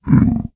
sounds / mob / zombiepig / zpig3.ogg